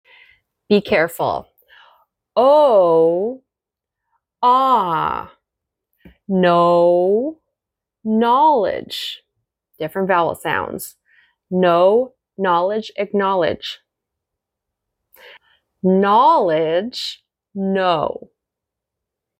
⚠HOW TO PRONOUNCE "KNOW" vs. "KNOWLEDGE" in English with a Canadian/American Accent ***inspired by a follower 👩‍🏫ANNOUNCEMENTS ➡📆 Accepting new 1-on-1 American Accent clients starting September.